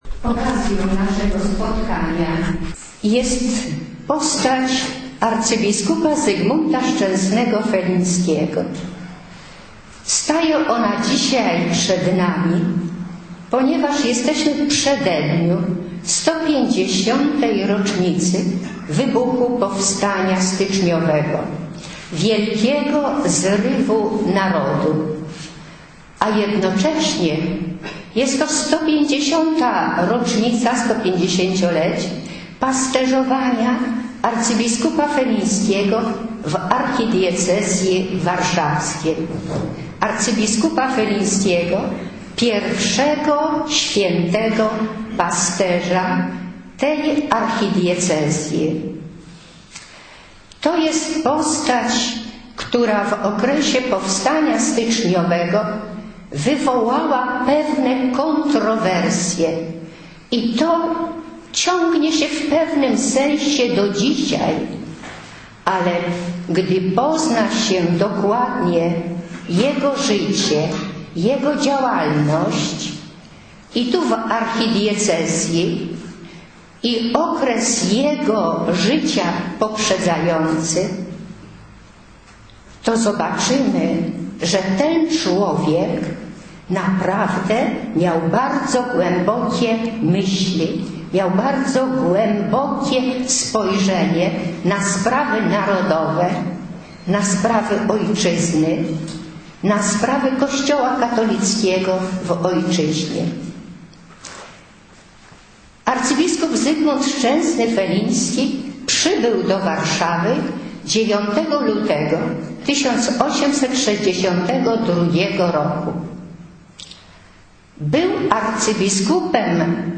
GALERIA FOTO NAGRANIE PRELEKCJI